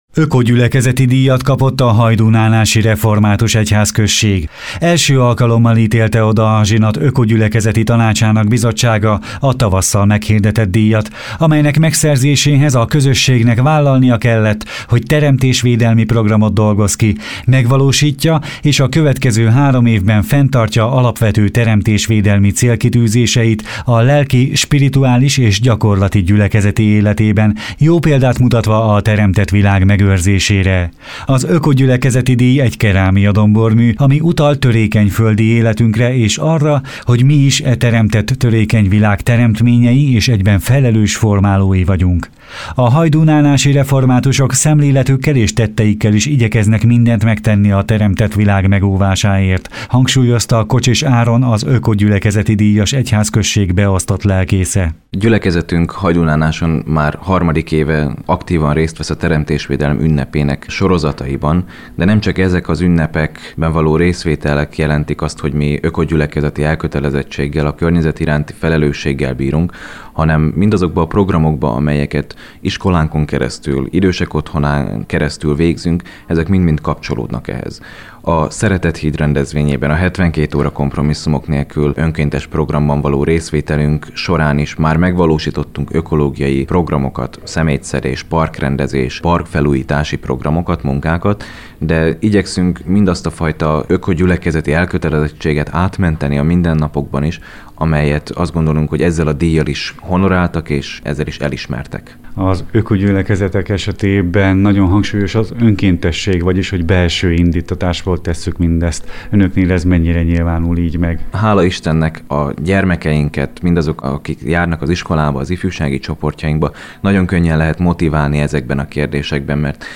A helyszínen készített riport-összeállítást itt meghallgathatják.